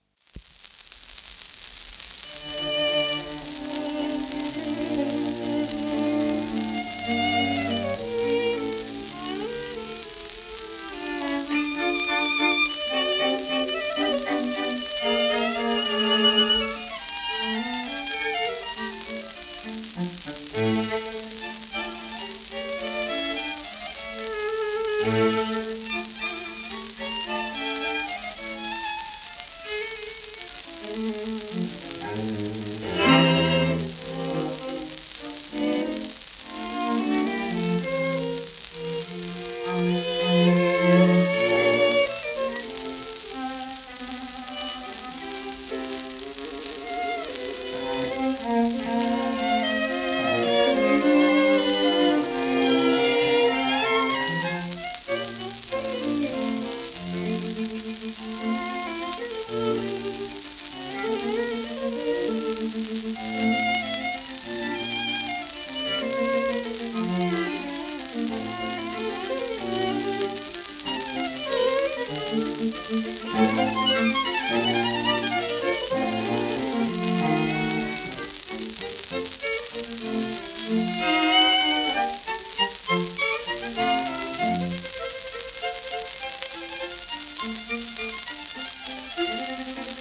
フロンザリー弦楽四重奏団
盤質A- *盤反り有,レーベルスタンプ
1929年録音